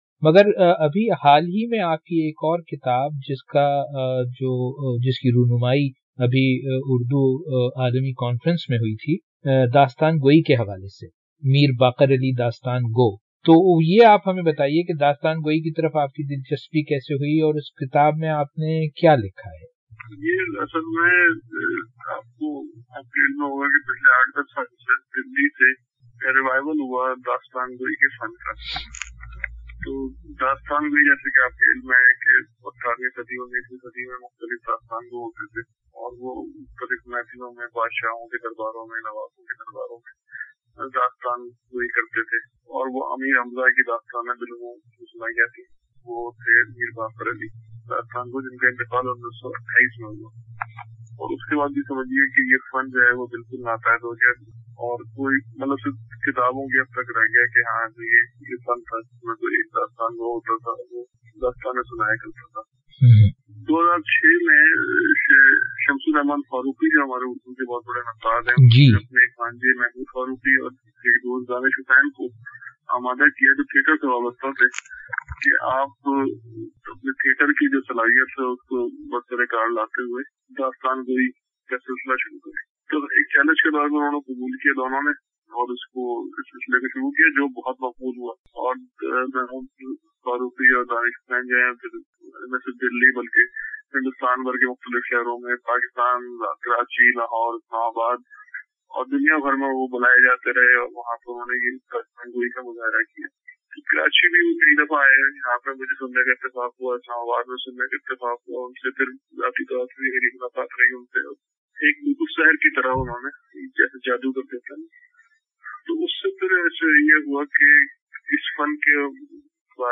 Valueversity recently had a brief conversation about his life and works.